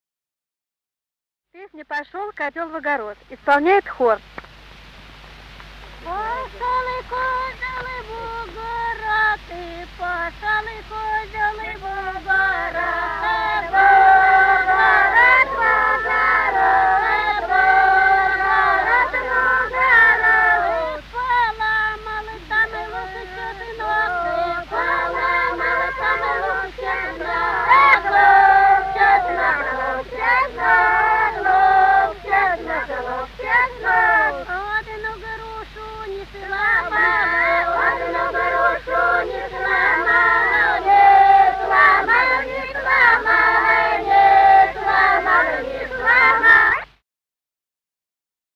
Русские народные песни Владимирской области 24. Пошёл козёл в огород (плясовая на свадьбе) с. Сельцо Суздальского района Владимирской области. Исполняет ансамбль.